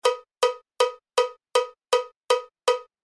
tempo=160
tempo160.mp3